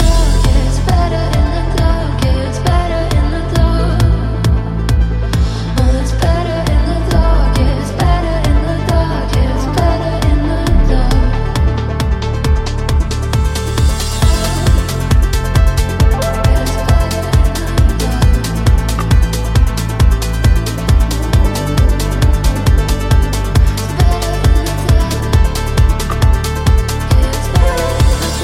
Genere: pop,dance,deep,disco,house.hit